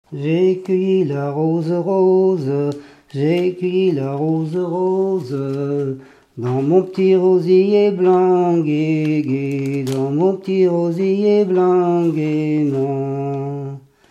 chanté pour aller à la noce en charrette
Pièce musicale inédite